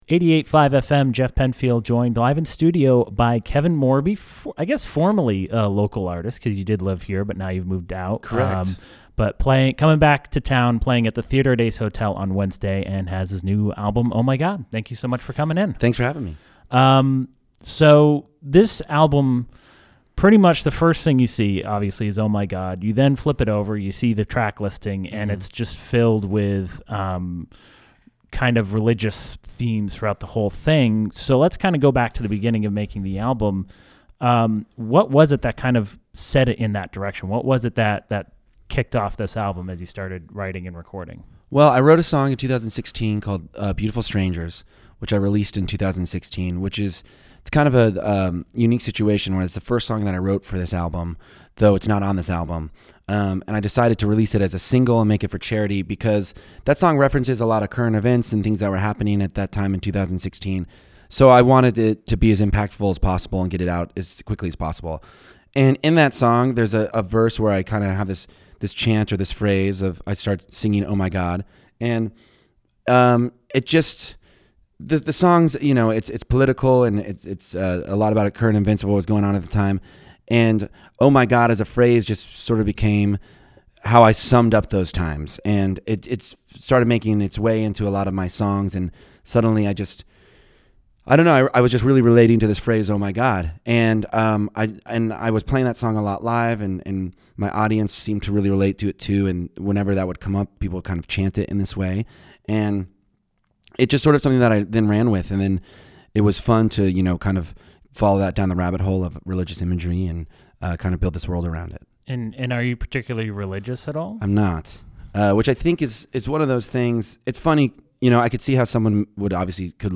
Los Angeles's public alternative rock radio.